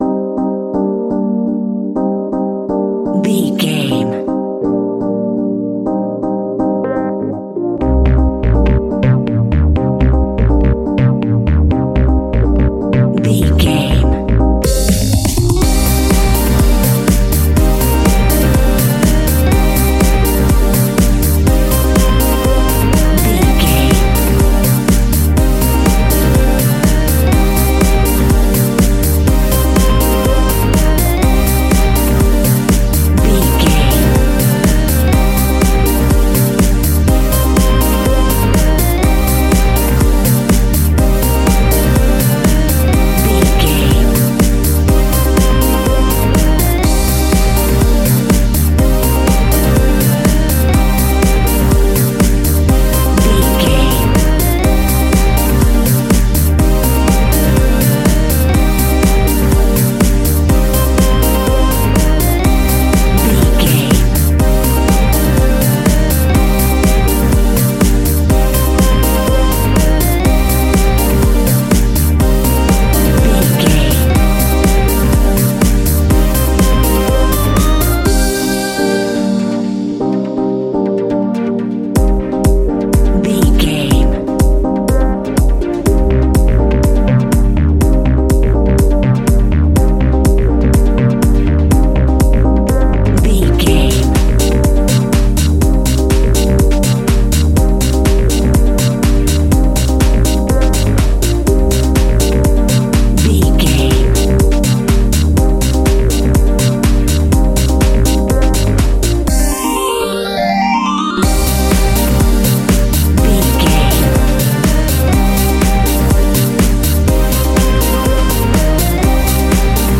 Aeolian/Minor
hypnotic
groovy
uplifting
electric piano
drums
disco house
electronic funk
energetic
upbeat
synth bass
synth lead
Synth pads
bass guitar
clavinet
horns